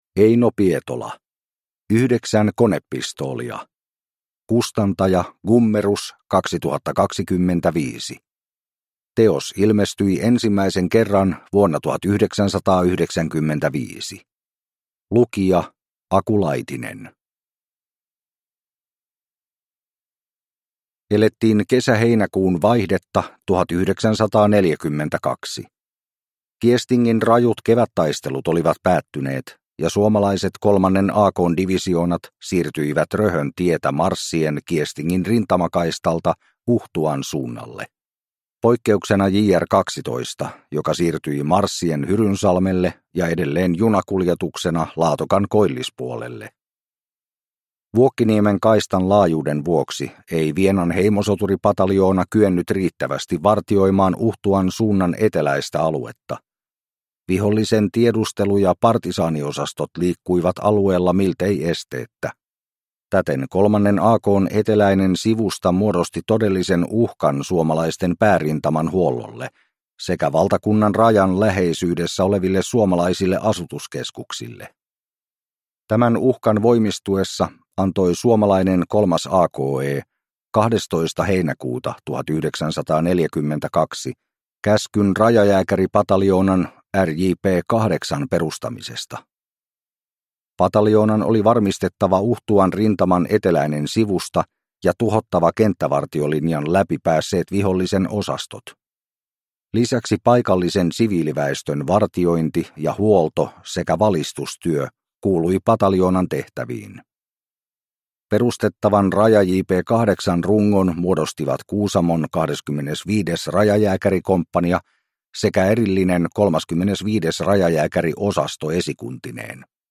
Yhdeksän konepistoolia – Ljudbok